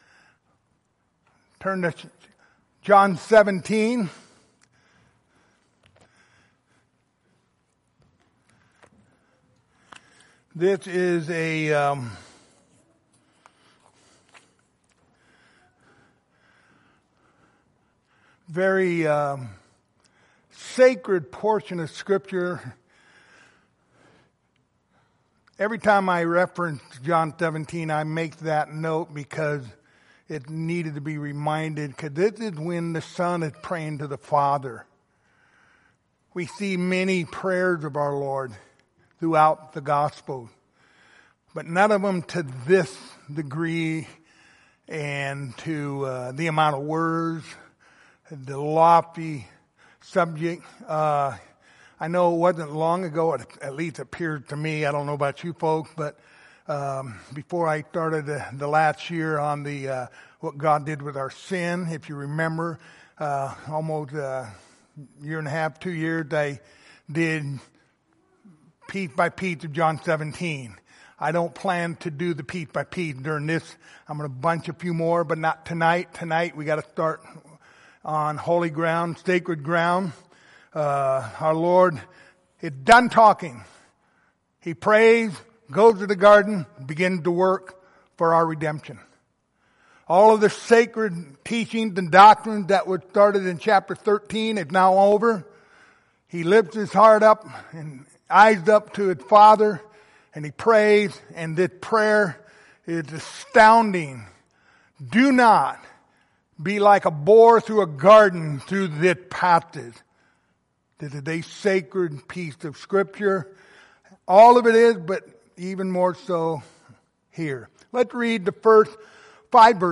The Gospel of John Passage: John 17:1-5 Service Type: Wednesday Evening Topics